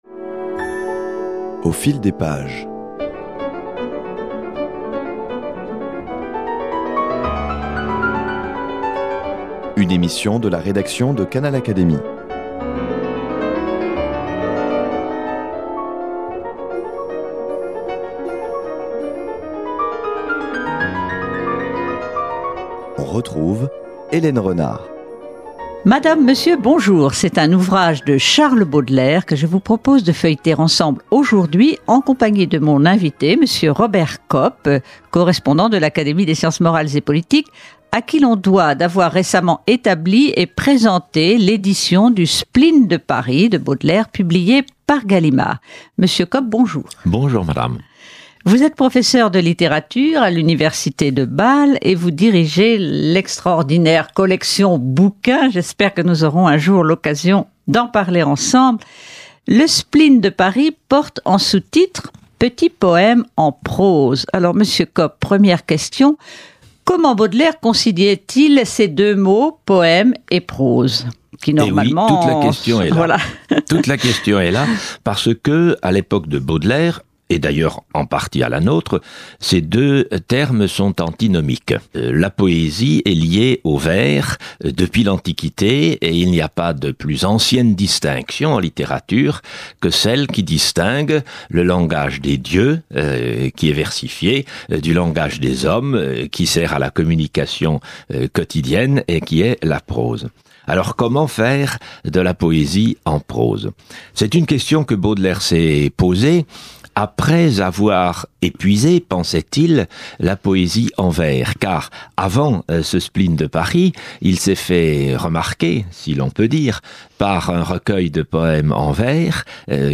Lectures par le comédien